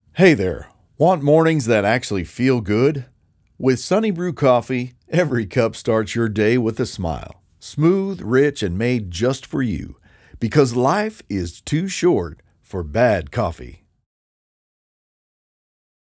Online Ad - SunnyBrew Demo
North American English, British (general)
- Professional recording studio and analog-modeling gear